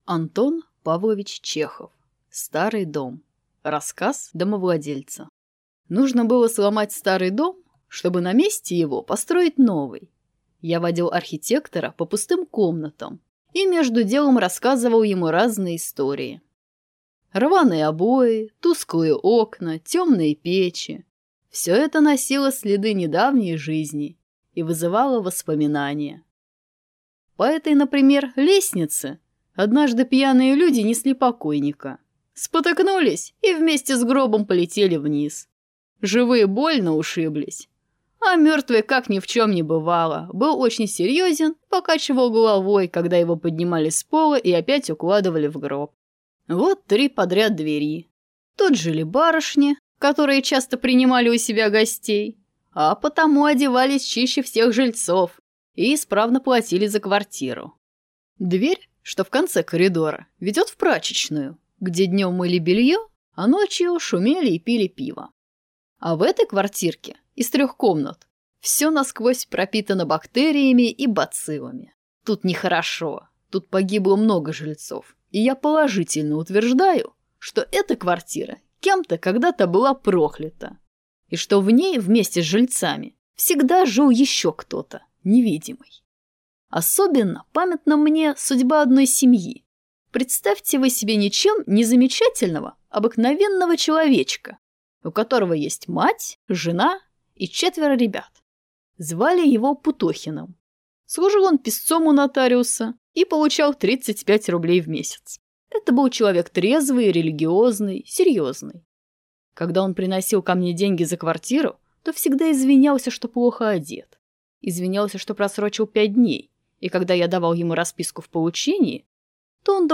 Аудиокнига Старый дом | Библиотека аудиокниг